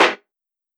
Snare